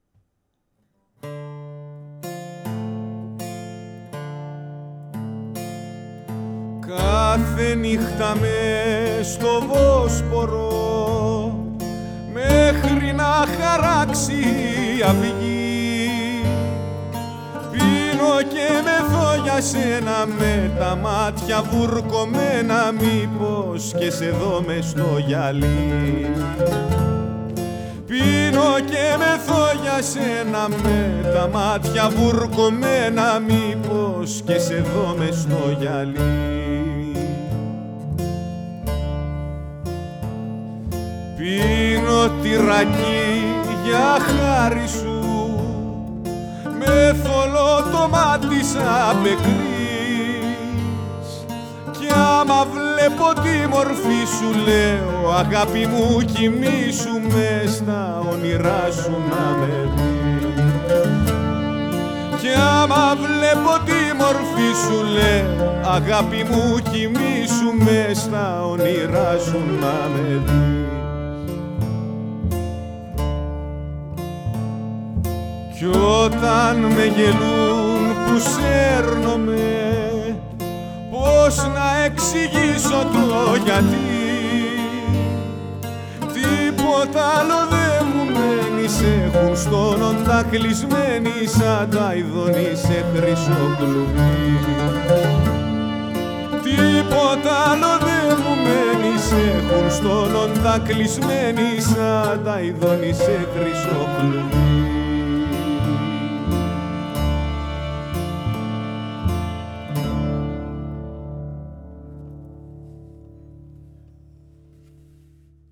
τραγουδάει